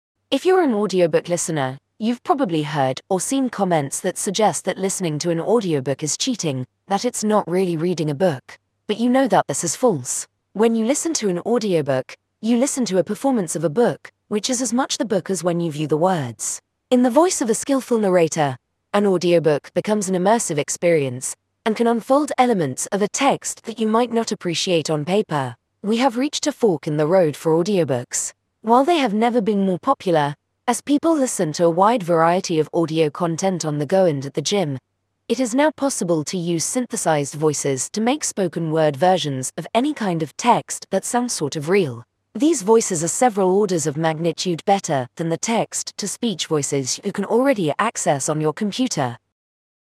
The first is one of Apple’s Siri voices, with a UK English accent
siri-voice.mp3